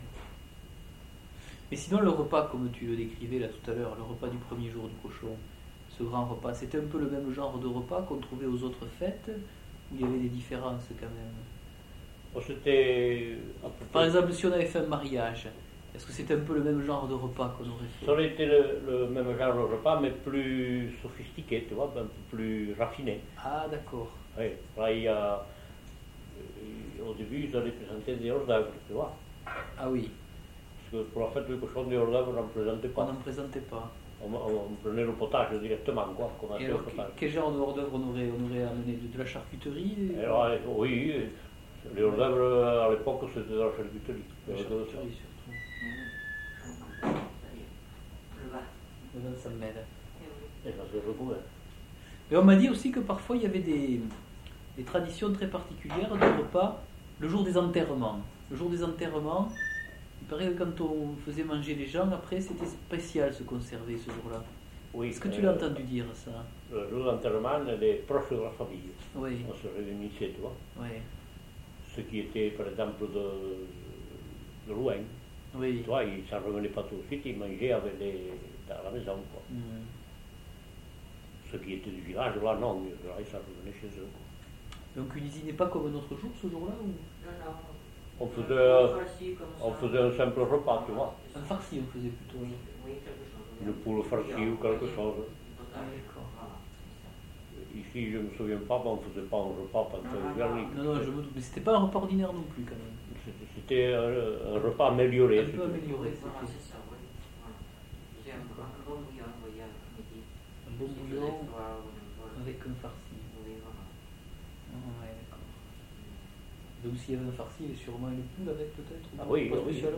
Lieu : Pouech de Luzenac (lieu-dit)
Genre : témoignage thématique